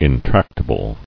[in·trac·ta·ble]